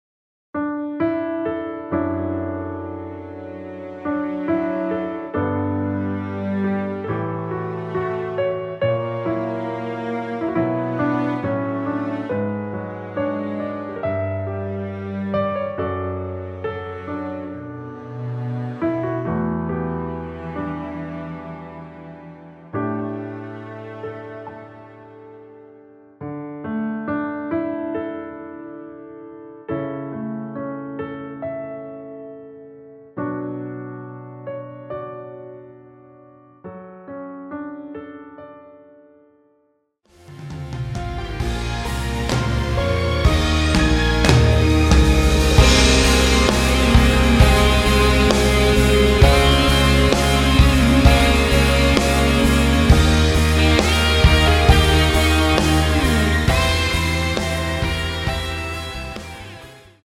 원키에서(-9)내린 MR입니다.
앞부분30초, 뒷부분30초씩 편집해서 올려 드리고 있습니다.
중간에 음이 끈어지고 다시 나오는 이유는